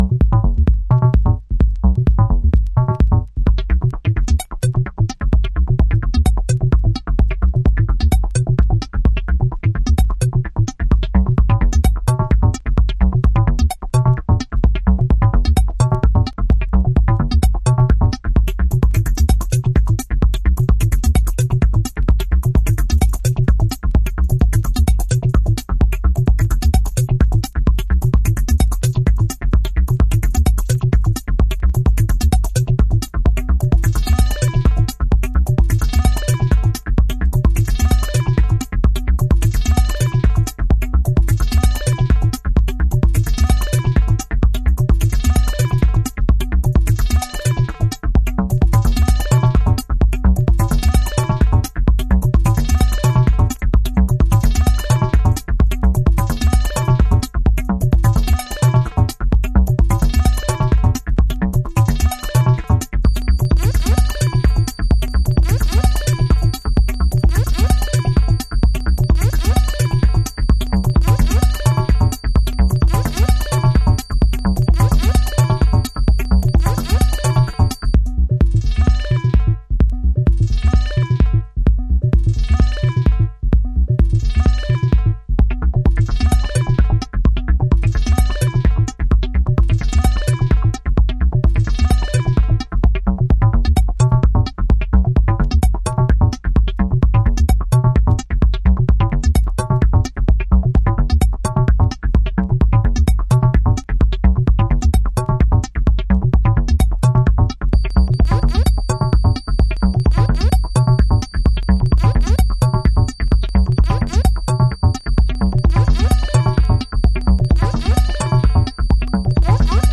House / Techno
メロディックテクノ。